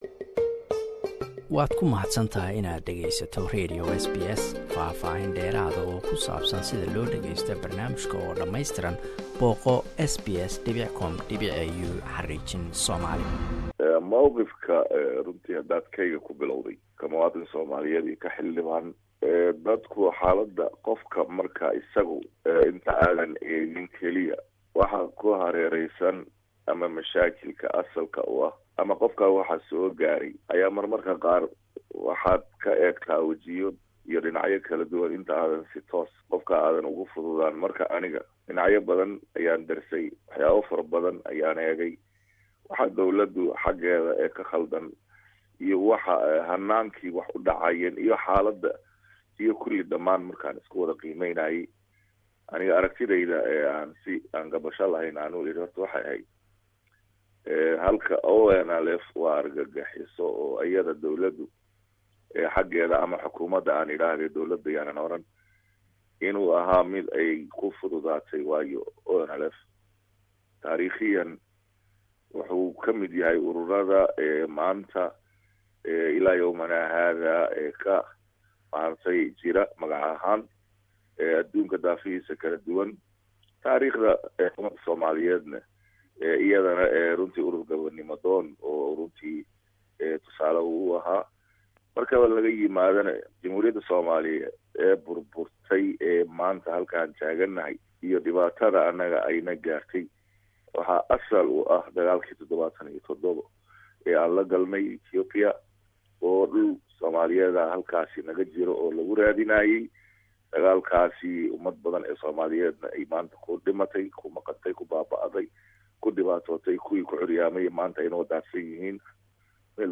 Qaybtii 2 aad waraysi: Xildhibaan Dalxa